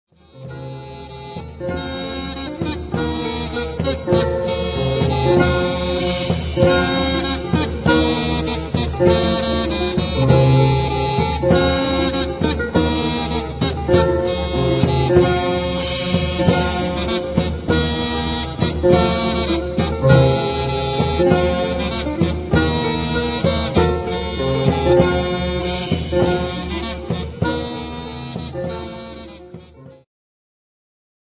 ジャンル Progressive
リュート
管楽器フューチュア
弦楽器系
古楽
癒し系
中世・ルネサンス・初期バロックの曲をブロークンコンソート形式で演奏。